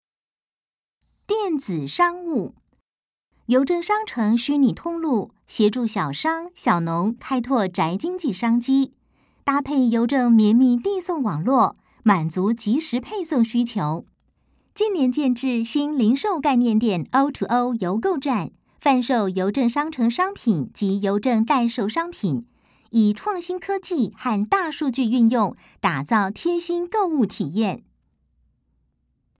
國語配音 女性配音員